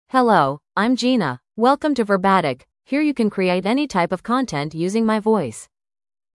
Gina — Female English (United States) AI Voice | TTS, Voice Cloning & Video | Verbatik AI
FemaleEnglish (United States)
Gina is a female AI voice for English (United States).
Voice sample
Female
Gina delivers clear pronunciation with authentic United States English intonation, making your content sound professionally produced.